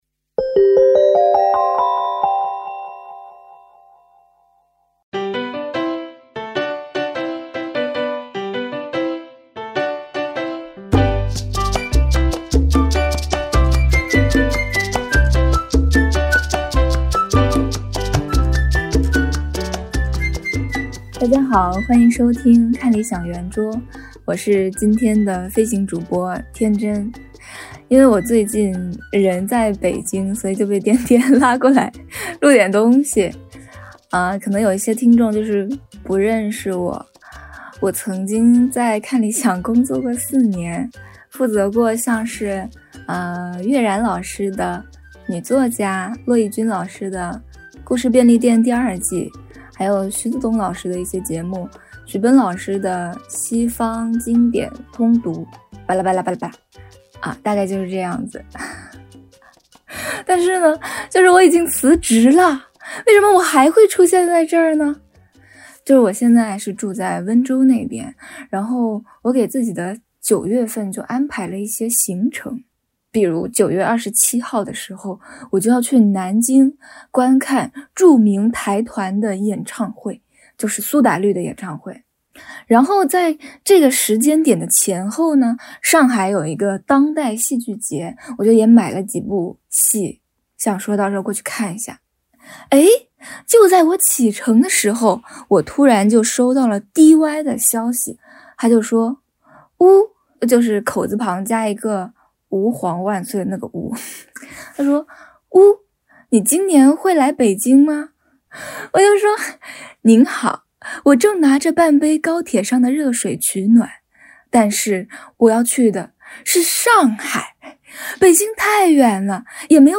看理想出品，入选“2019 Apple 最佳播客”，每周四更新，编辑和主讲人放飞自我的圆桌聊天，时不时也招呼各个领域的好朋友们来坐一坐，和你一起听见生活更多可能。